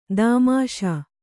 ♪ dāmāṣā